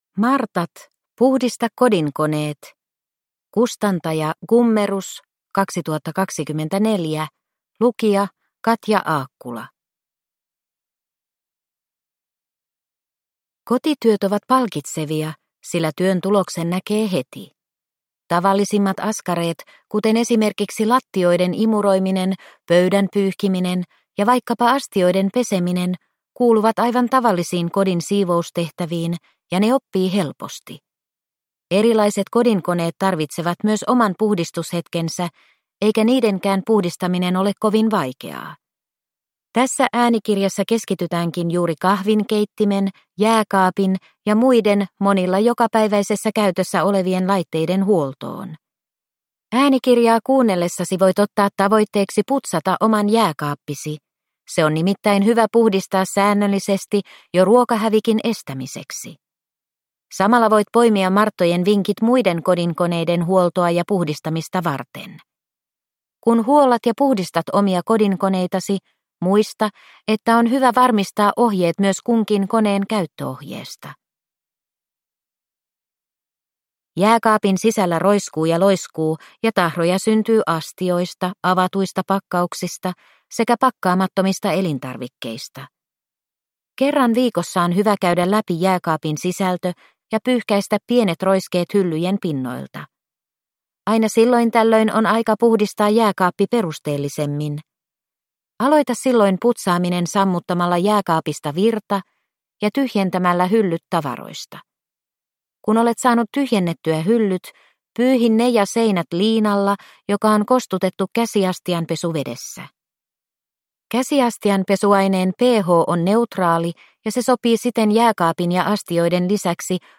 Martat - Puhdista kodinkoneet – Ljudbok